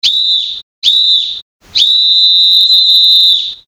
FUN kvíz: Píšťaľka | Fun rádio
Napíšte nám, čo znamená vo väčšine prípadov nižšieuvedené písknutie rozhodcu na futbale.
Čo znamená toto písknutie futbalového rozhodcu?